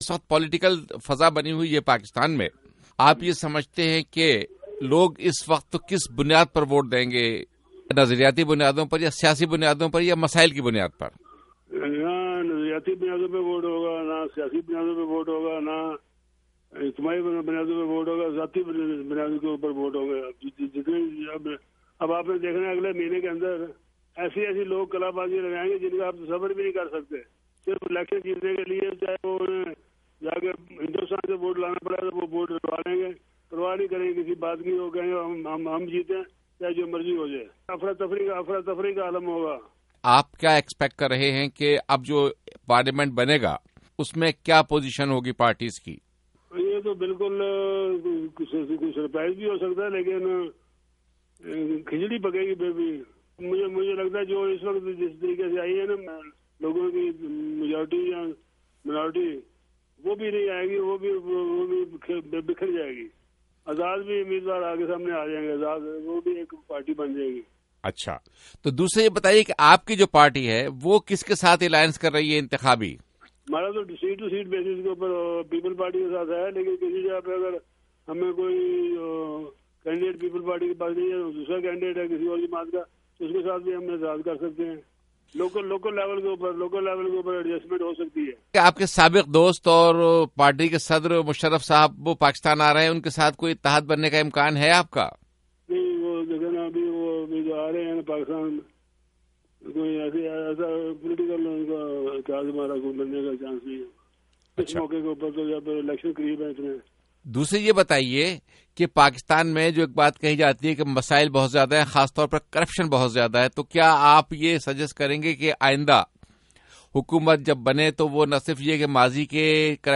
چودھری شجاعت حسین کا انٹرویو